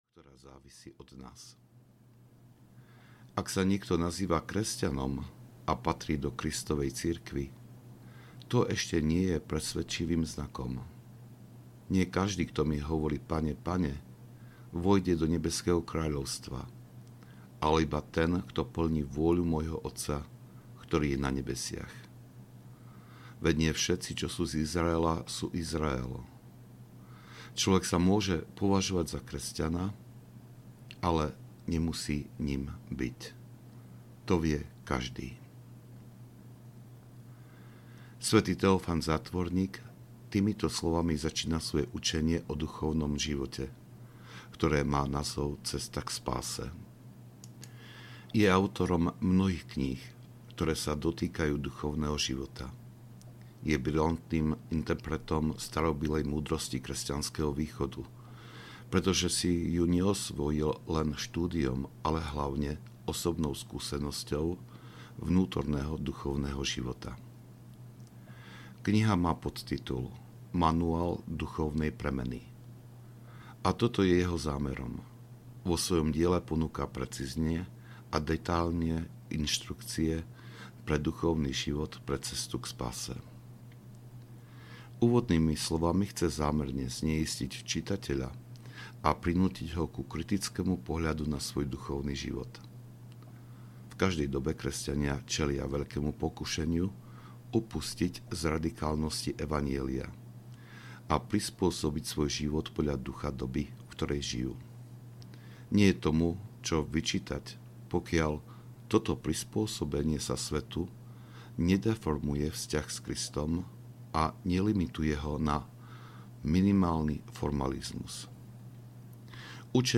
Ako sa v nás začína kresťanský život? audiokniha
Ukázka z knihy